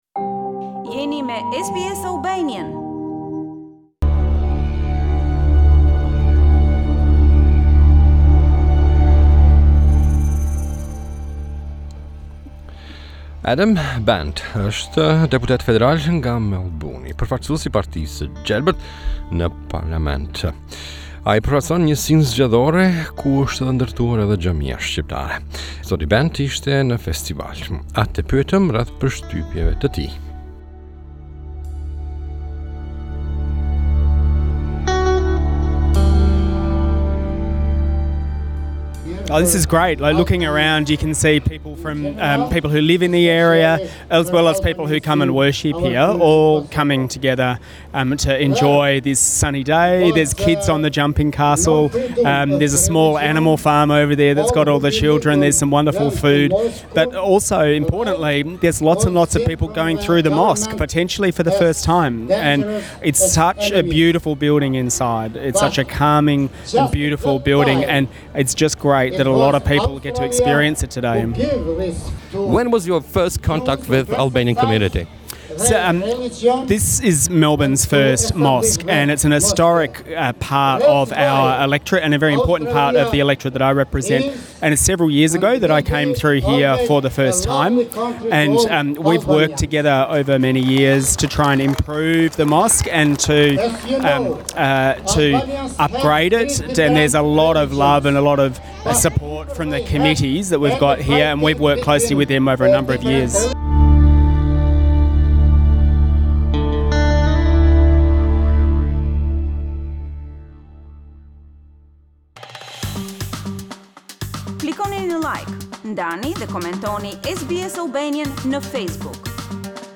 Adam Bandt - Federal Member for Melbourne at 50th Anniversary of the Albanian Mosque